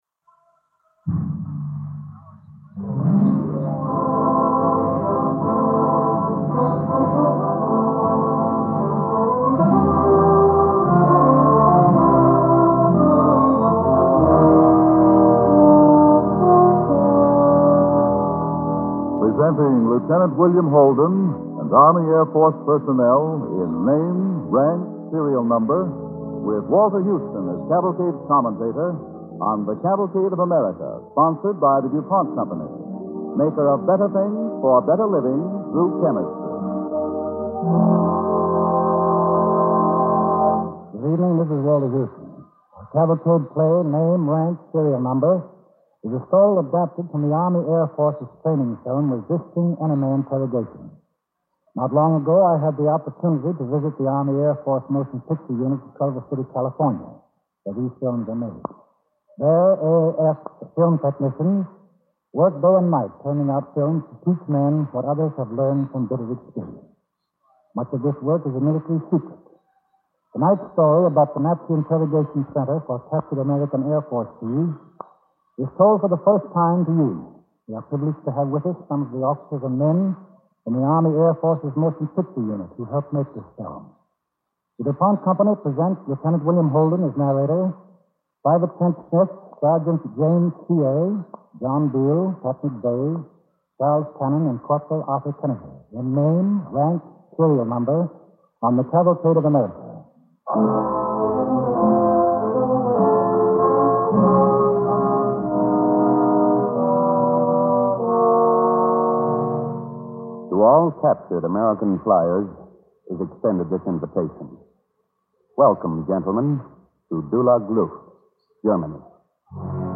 Name, Rank and Serial Number, starring Lieutenant William Holden with host Walter Houston